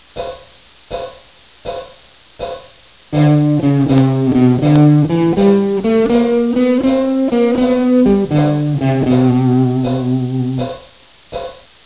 Пример 3 дает вам представление о том, как я могу составить одно целое из стилей Чарли и своего собственного.